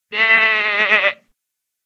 PixelPerfectionCE/assets/minecraft/sounds/mob/sheep/say3.ogg at mc116